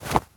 foley_cloth_light_fast_movement_06.wav